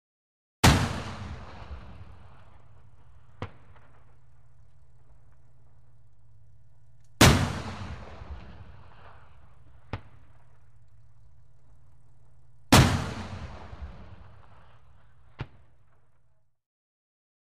В коллекции представлены мощные залпы из основной пушки, очередь из пулемета и звук отдачи.
Звуки танковых выстрелов